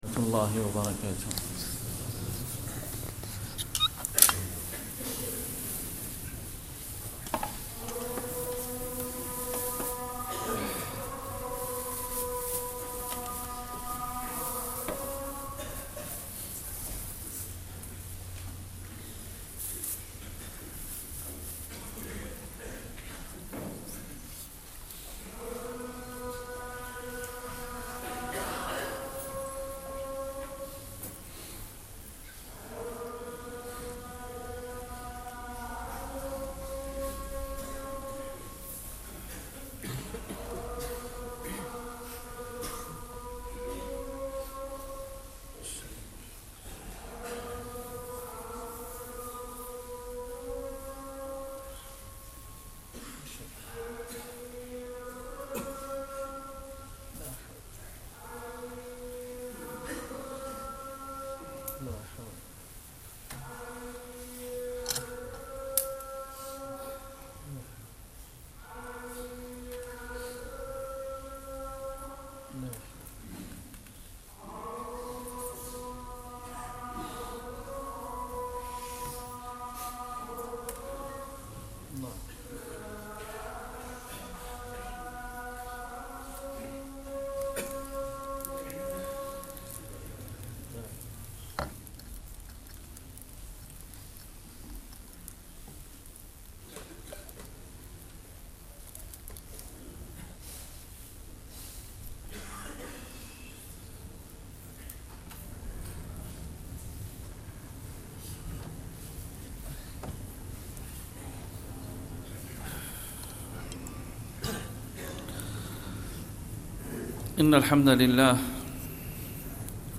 khotba-silat-rahim.mp3